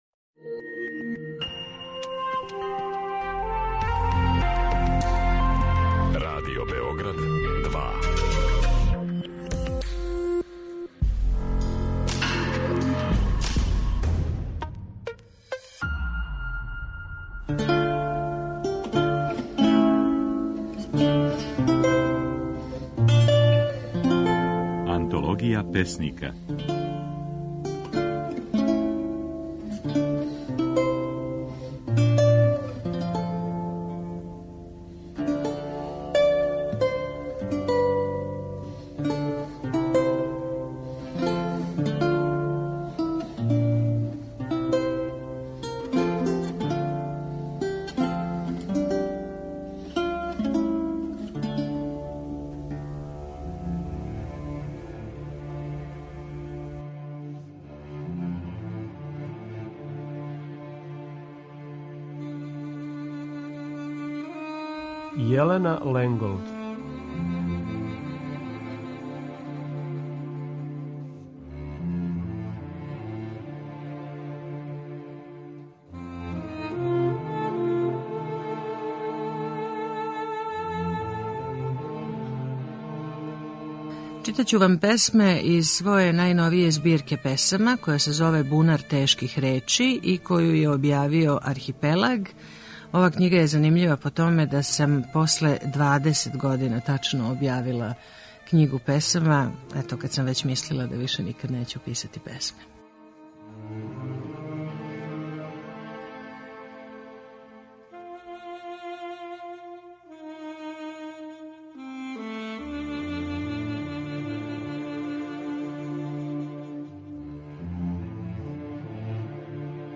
Можете чути како своје стихове говори Јелена Ленголд.